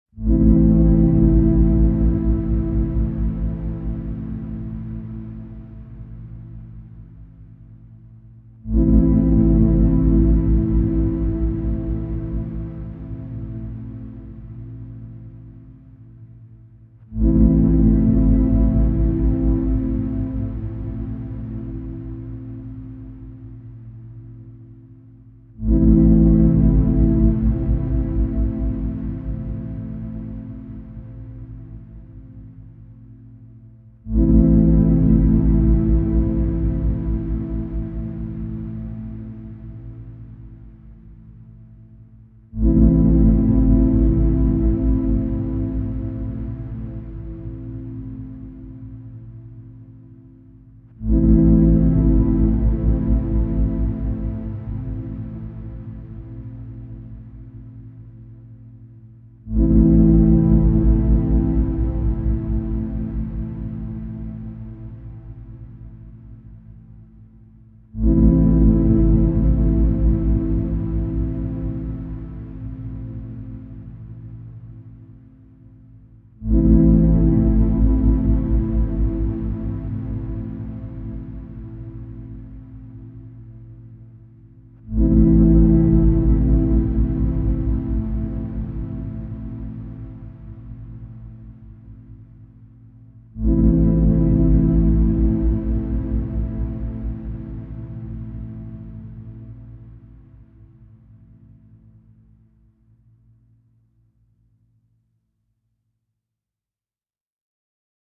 Dark Drone Version